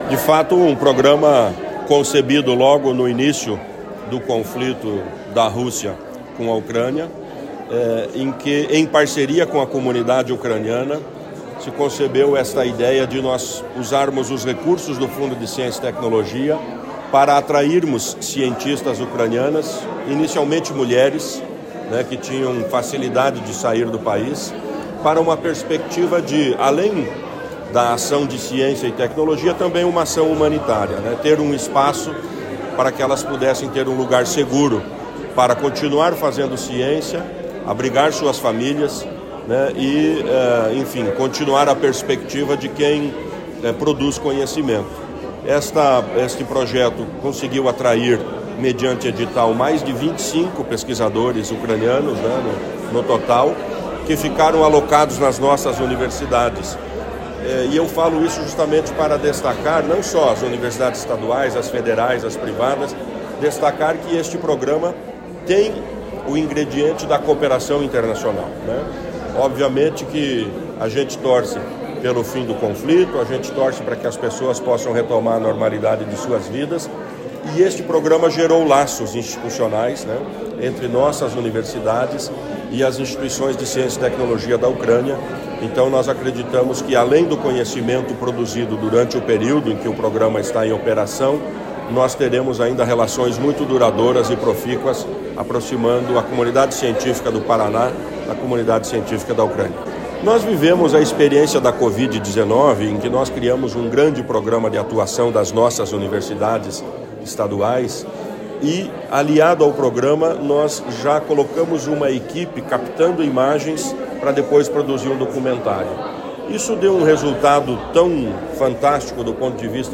Sonora do secretário da Ciência, Tecnologia e Ensino Superior, Aldo Bona, sobre o documentário que mostra trajetória de cientistas ucranianos acolhidos no Paraná